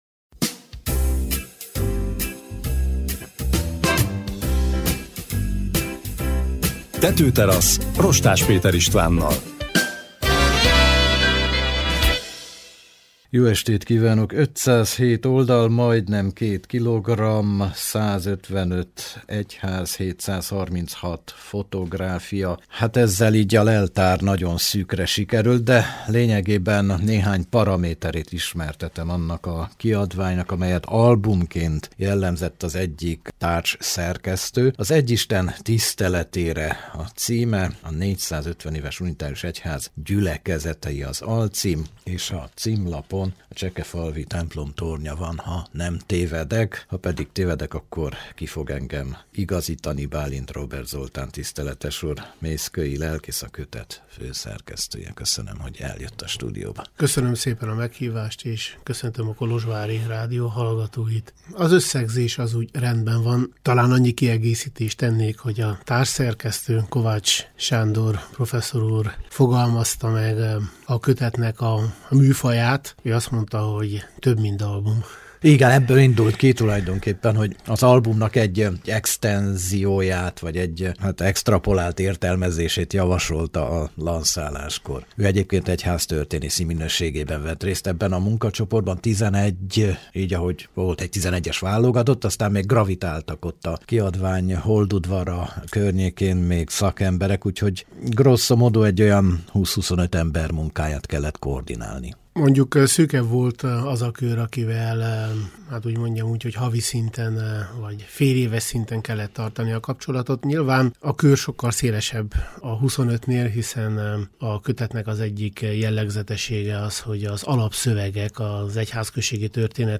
jött el a stúdióba az ötletről, a szerteágazó szerkesztési munkáról, a könyv vélhető hatásáról beszélni.